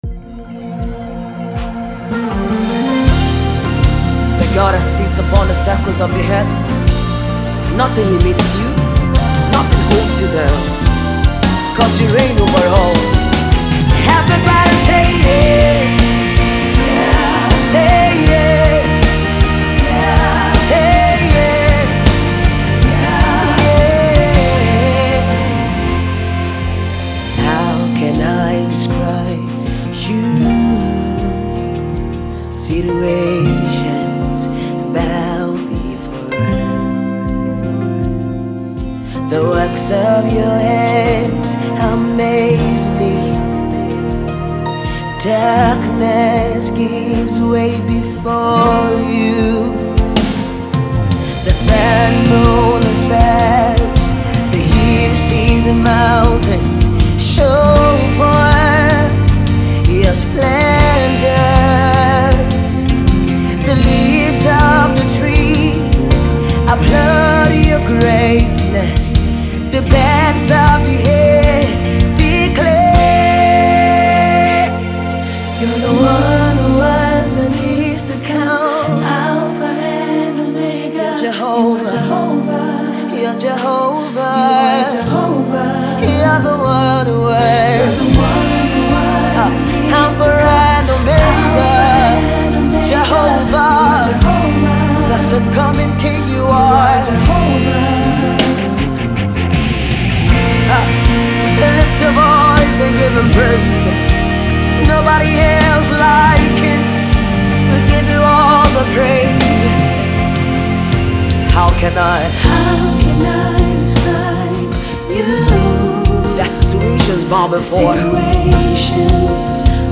gospel
slow rock song laced up with strings and keys